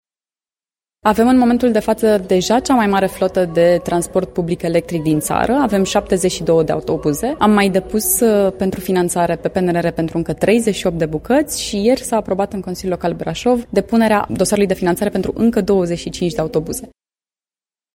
Viceprimarul Brașovului, Flavia Boghiu: